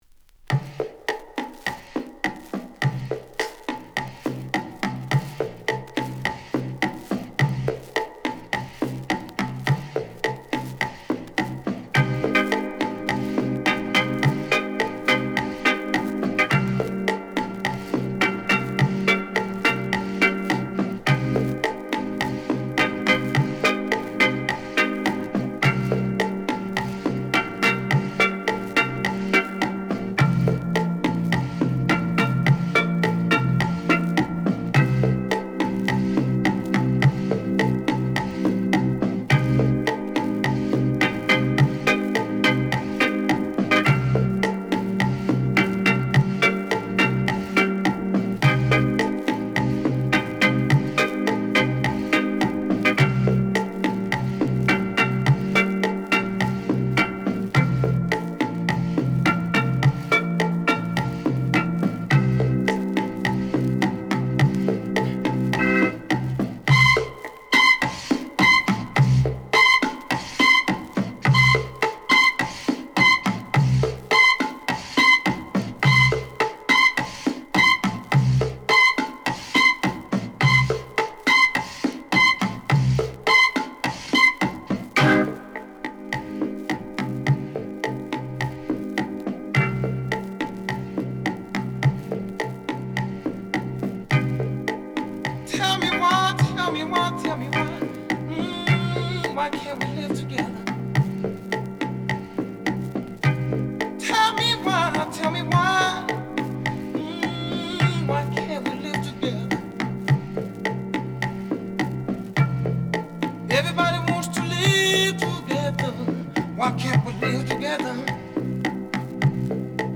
Genre: Disco.